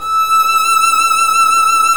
Index of /90_sSampleCDs/Roland - String Master Series/STR_Violin 1-3vb/STR_Vln1 % + dyn